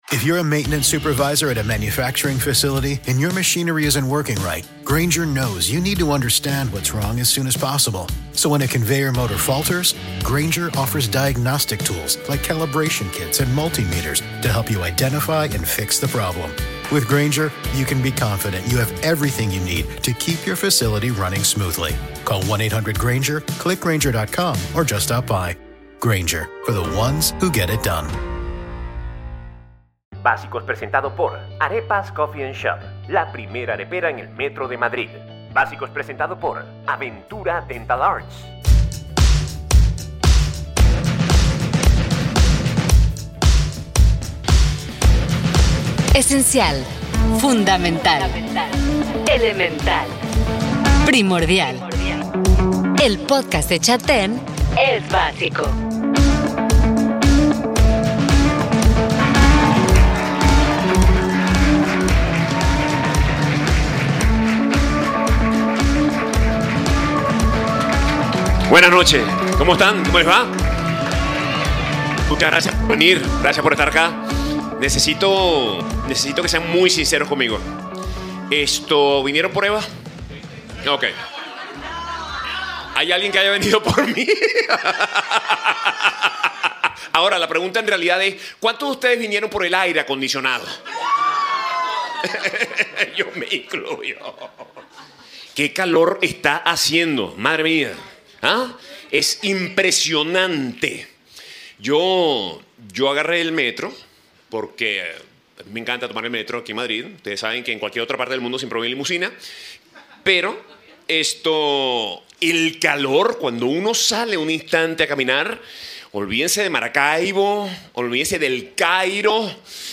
Este el tercero de una serie de 4 episodios grabados desde “Café La Palma” en la ciudad de Madrid